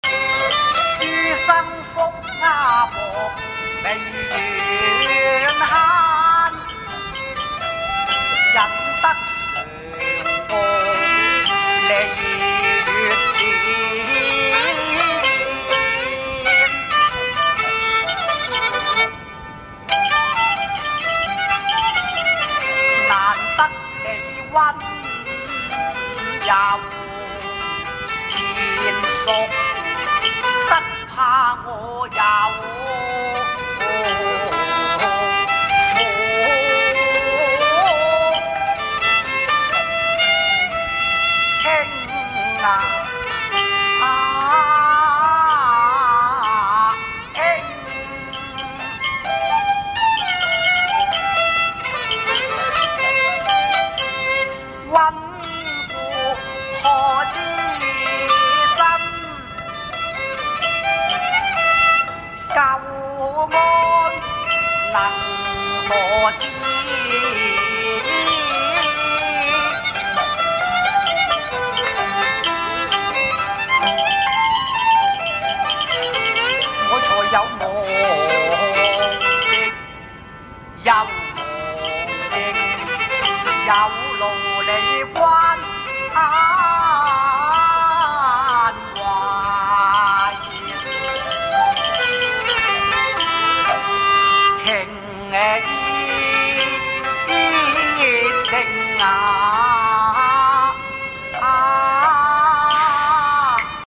三夕恩情 廿載仇 慢板